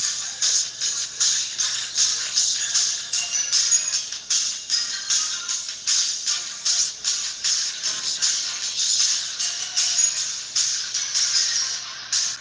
soa bem melhor em pessoa, mas :p
o microfone do celular não faz juz, haha